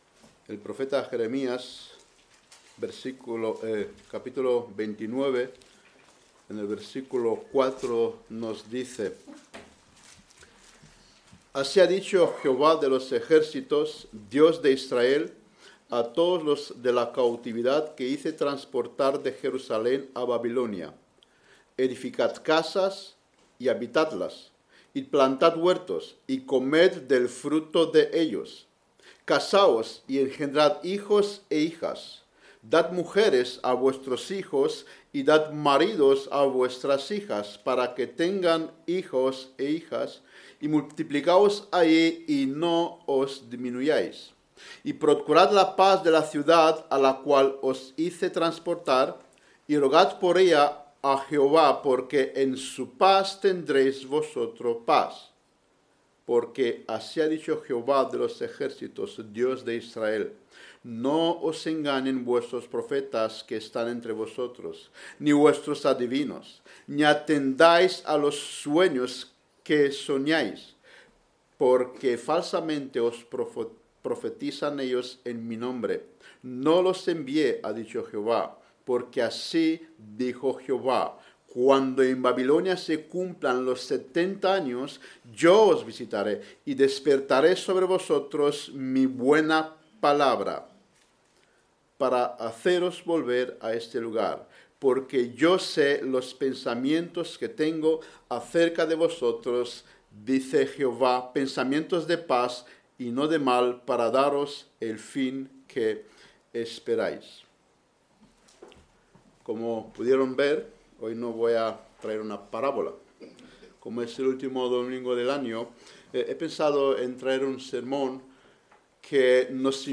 Sermones generales